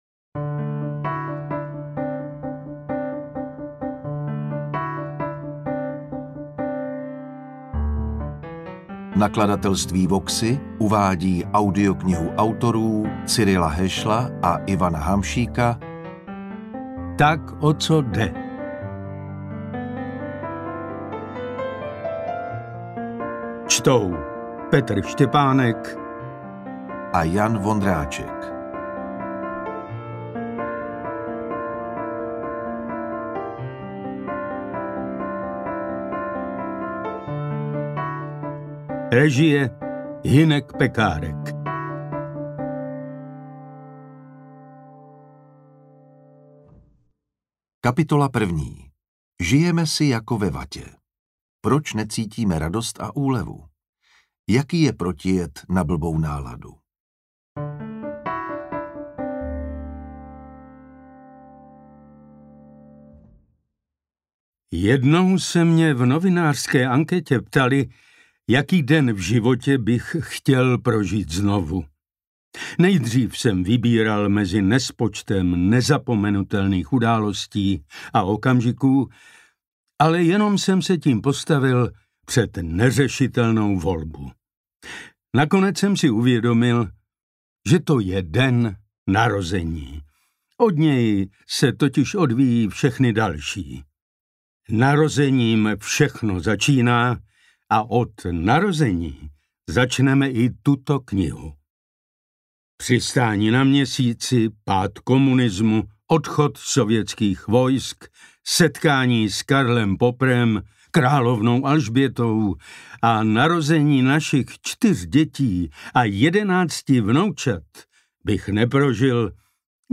Interpreti:  Petr Štěpánek, Jan Vondráček